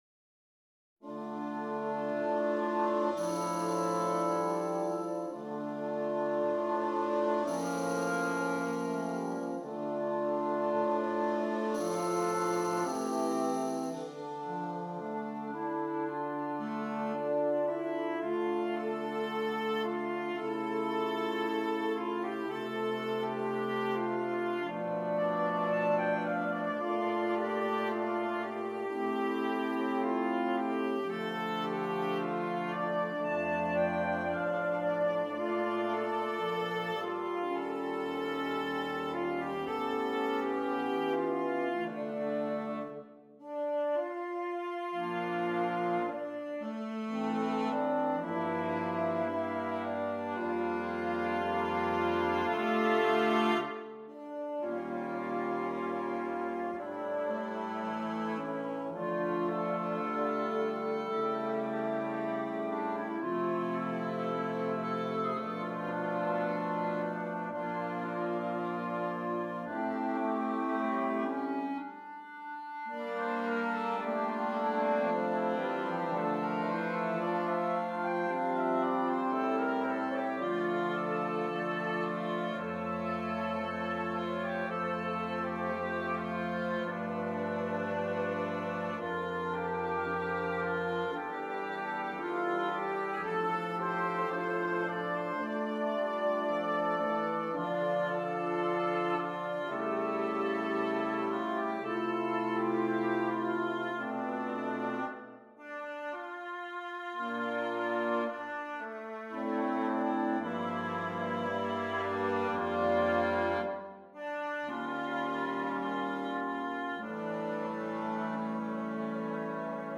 Interchangeable Woodwind Ensemble
Traditional